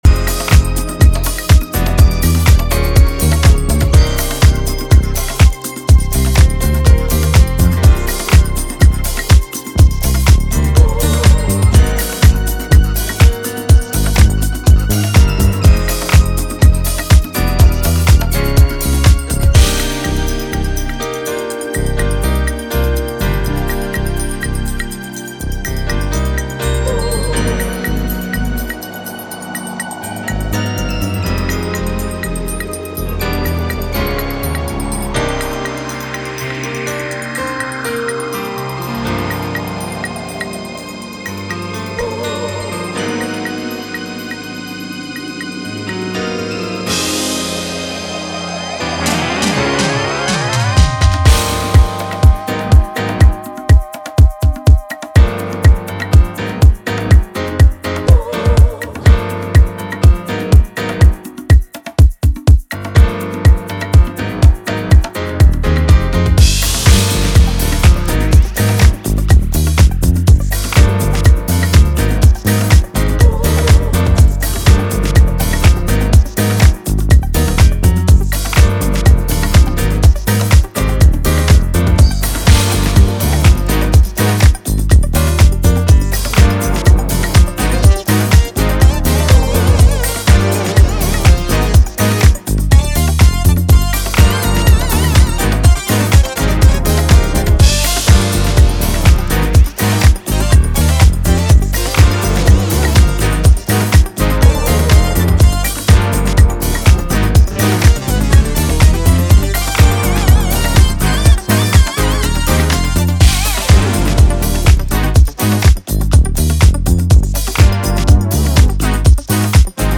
taking us on a cosmic Disco House flight.